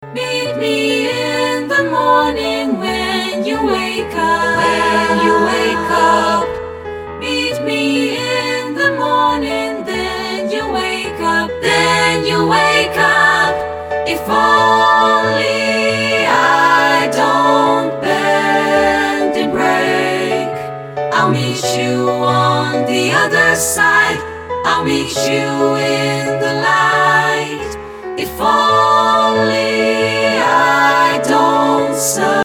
Een energiek SSAB‑arrangement
A dynamic SSAB arrangement